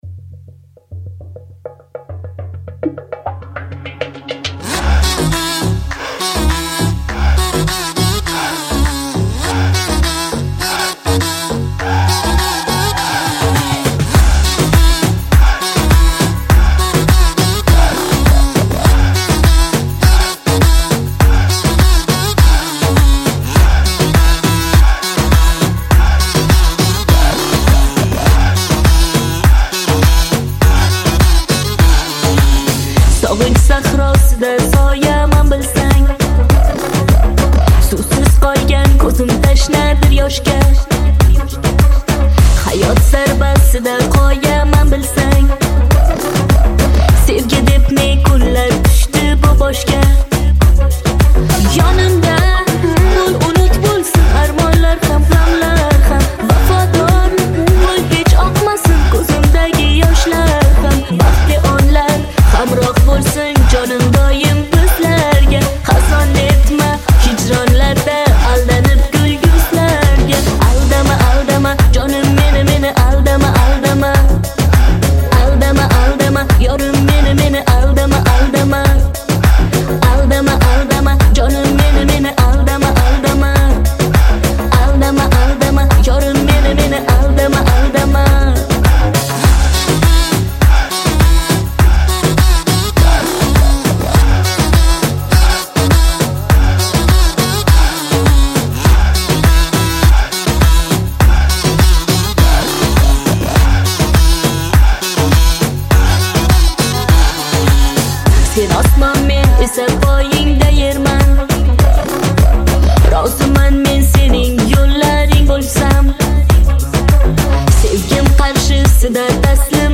• Жанр: New Uzb / Узбекские песни